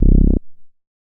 MoogResBall 004.WAV